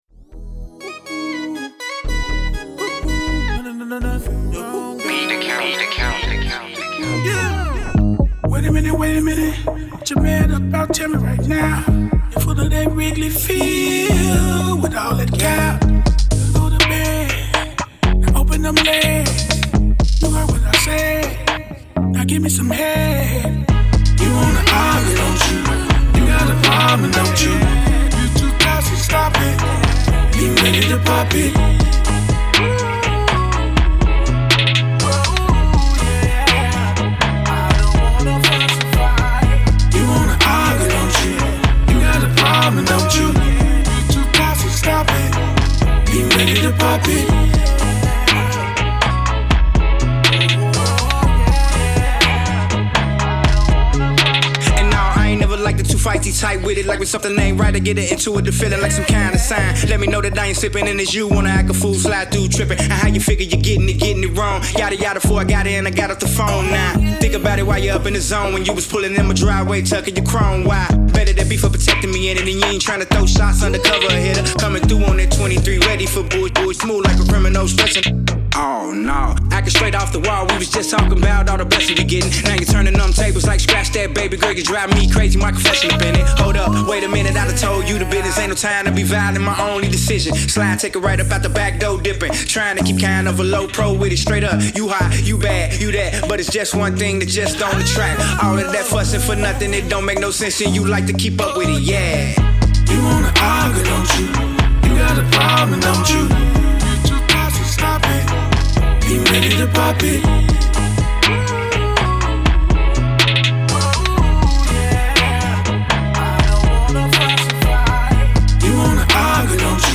Hiphop
creating an unforgettable hip-hop and R&B fusion experience.